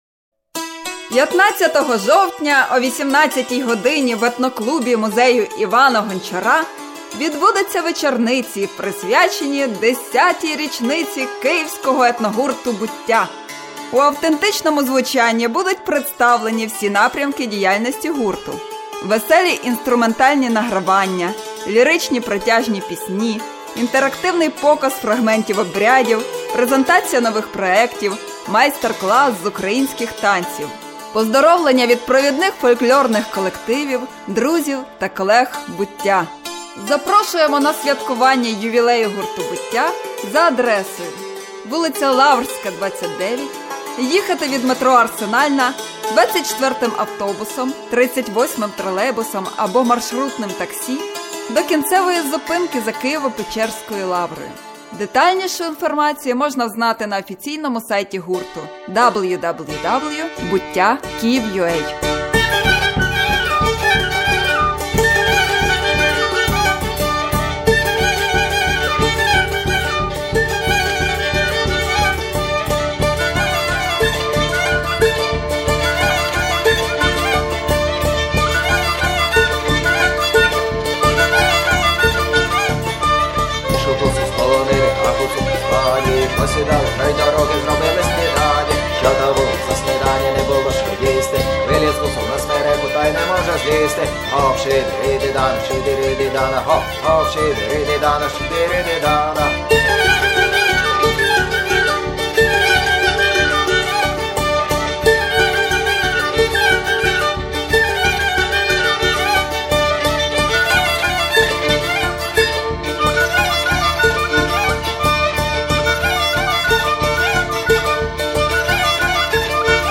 теґи: Етно-вечорниці, акапельний спів, етнічна музика, обряди, танці, ігри